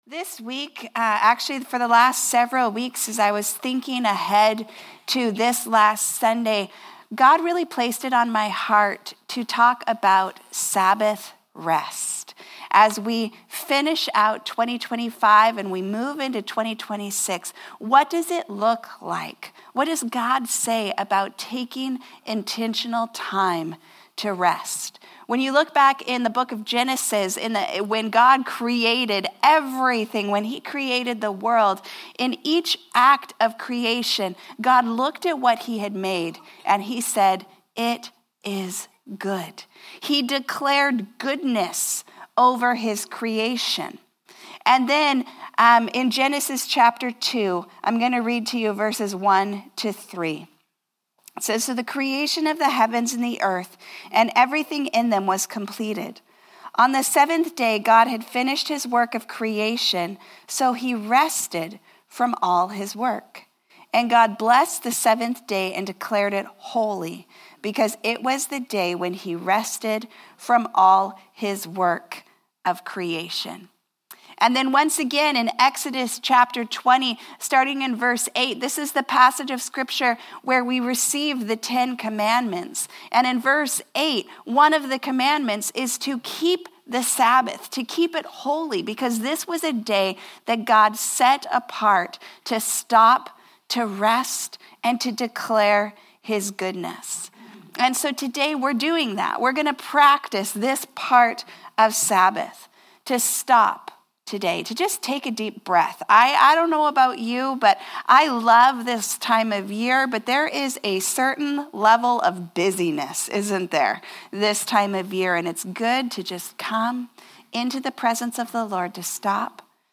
Sermons | Harvest Church
Following the teaching, some members of our church family will share brief testimonies of where they’ve seen God’s goodness and faithfulness in their lives throughout 2025.